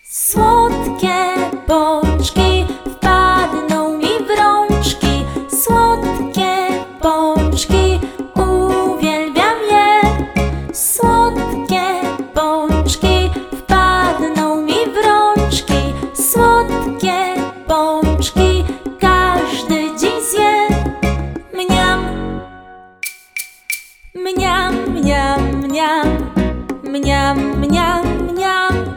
utwór w wersji wokalnej